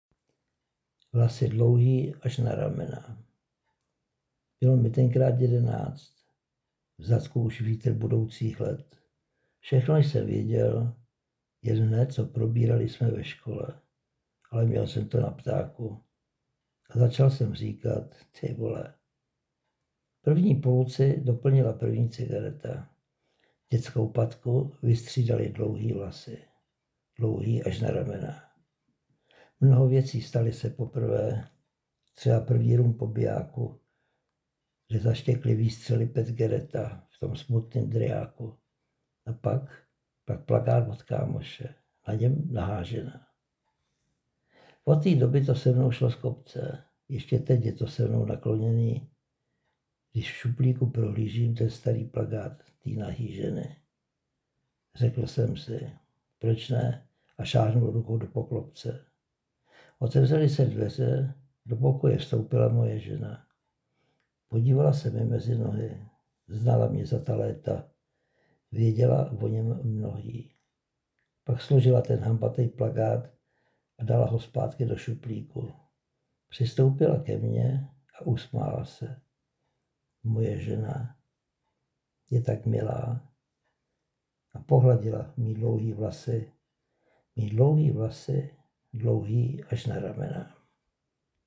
Mistrovské dílo, co víc říct. A moc pěkný přednes